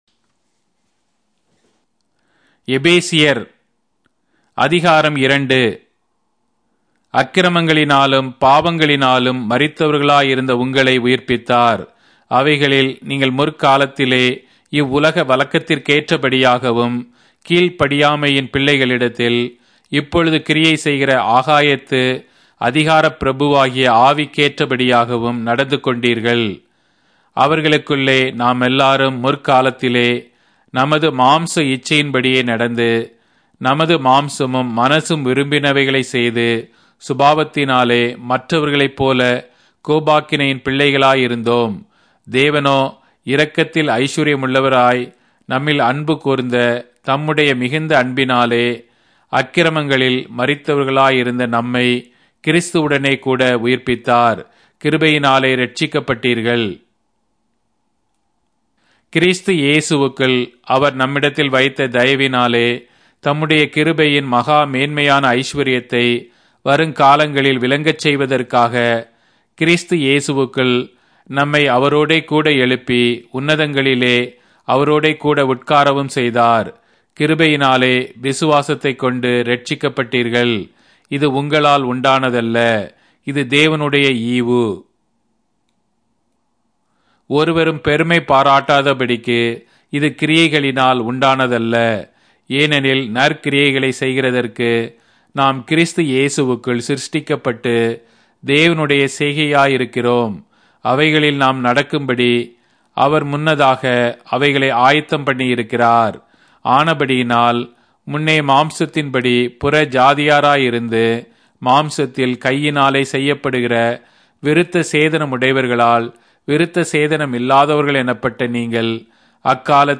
Tamil Audio Bible - Ephesians 2 in Ervbn bible version